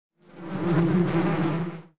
flies3.wav